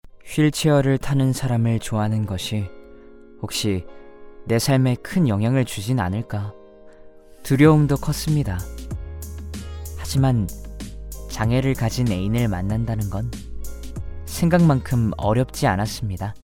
남자